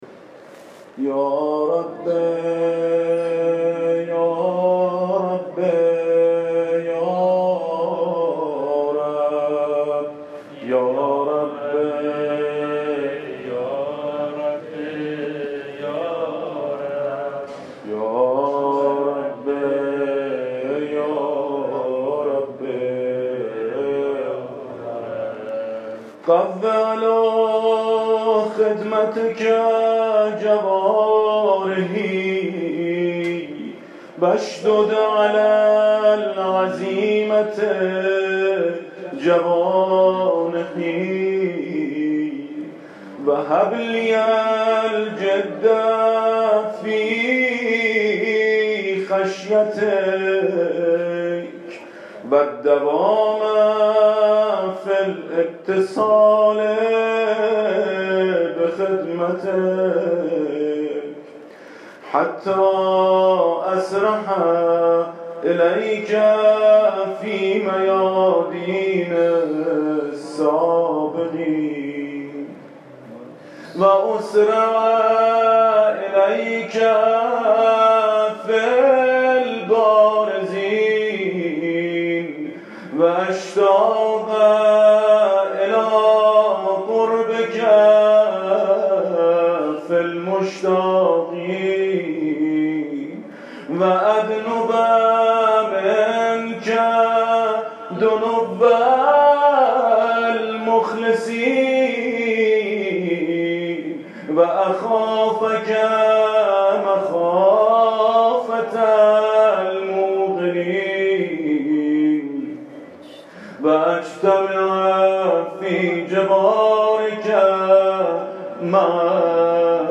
فراز پایانی دعاي كميل در بين دانشجويان ايراني در شب 19 ماه مبارک رمضان